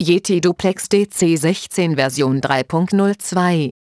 Sprachansagetext für neue Version V3.02